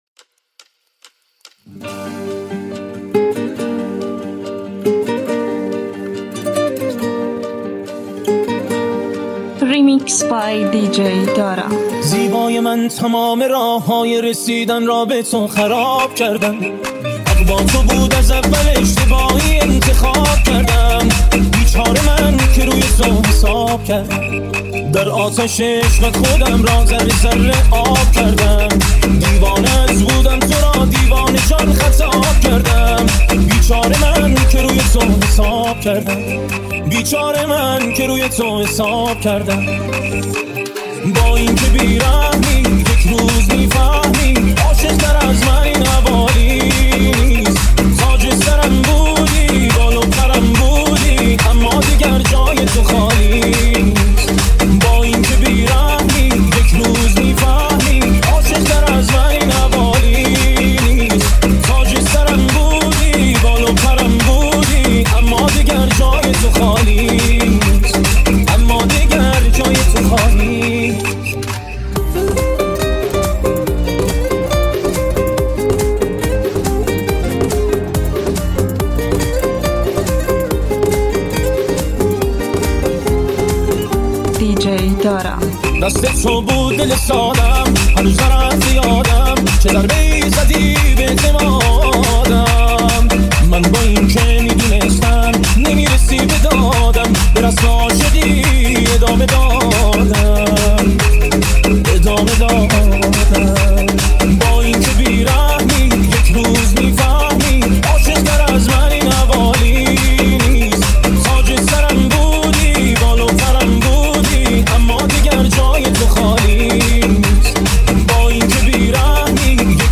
ریمیکس سوتی